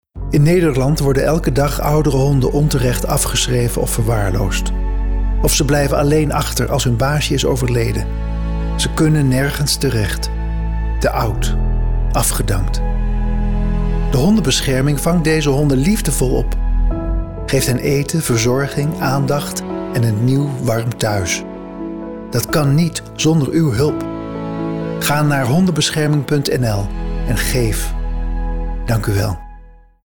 Beluister onze radiospot met Arthur Japin